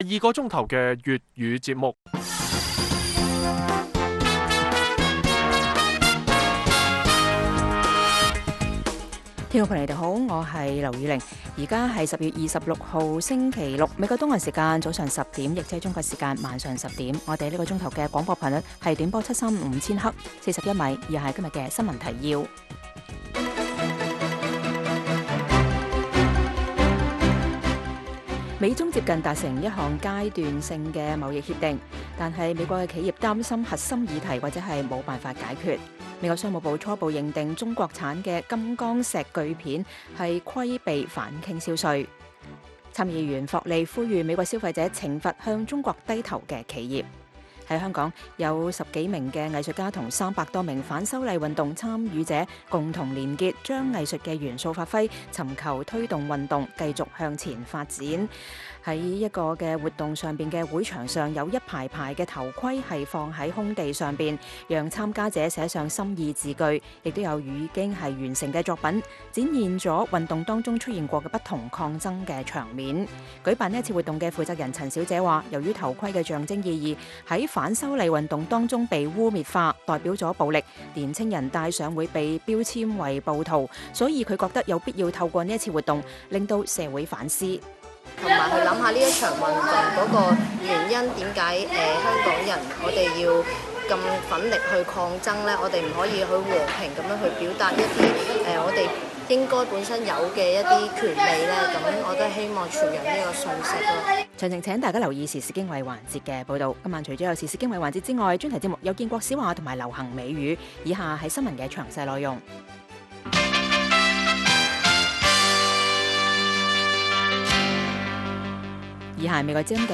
粵語新聞 晚上10-11點
北京時間每晚10－11點 (1400-1500 UTC)粵語廣播節目。內容包括國際新聞、時事經緯和英語教學。